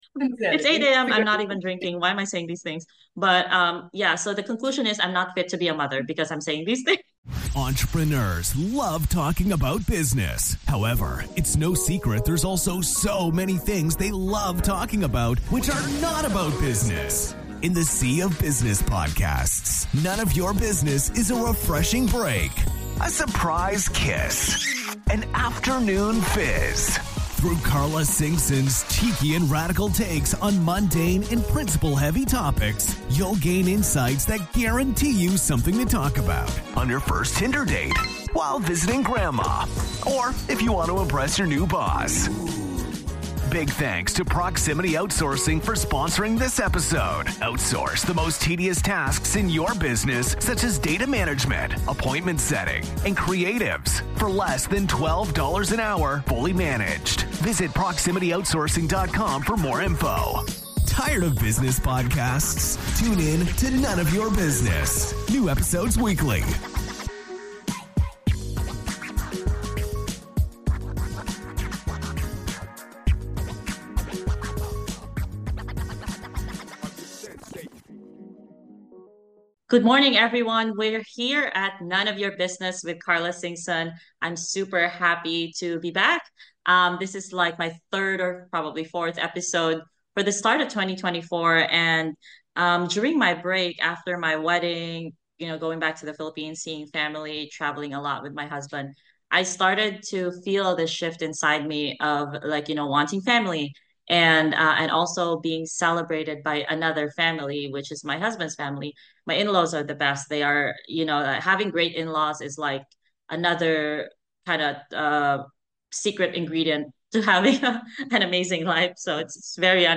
Should I be a Mom or Not? A Casual Chat Between Two Businesswomen